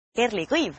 Kerli Kõiv (Estonian: [ˈkerli ˈkɤ.iʋ]